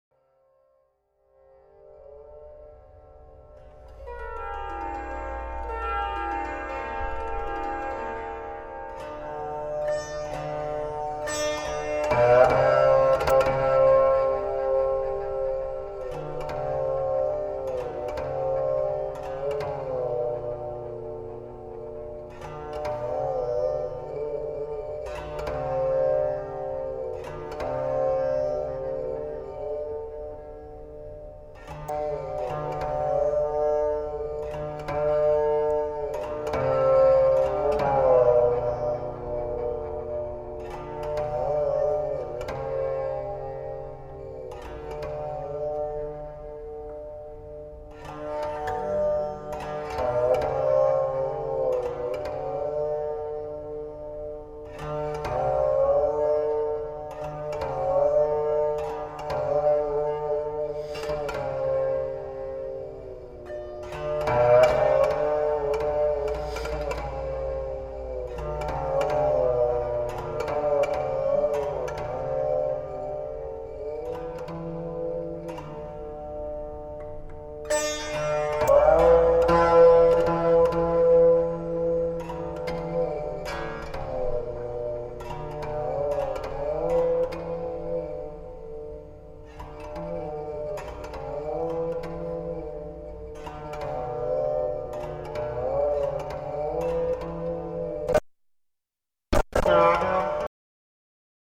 VICHITRA VINA
Indian Classical
alap